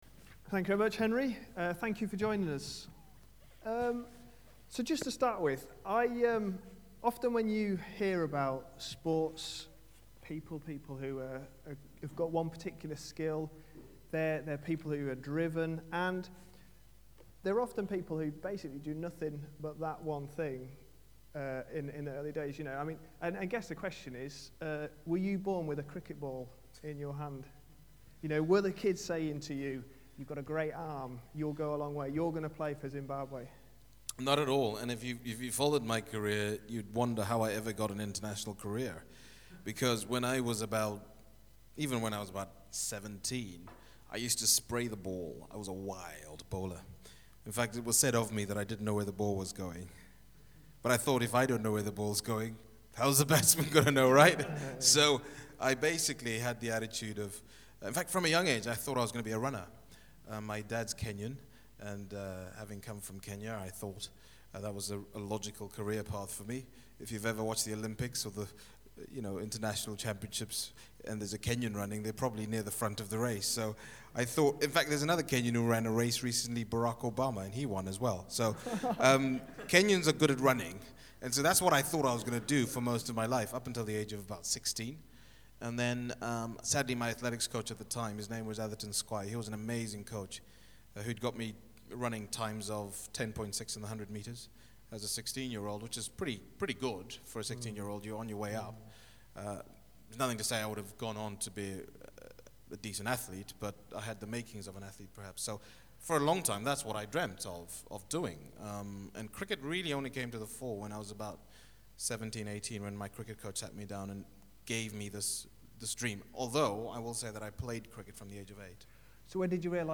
A sermon preached on 1st March, 2011.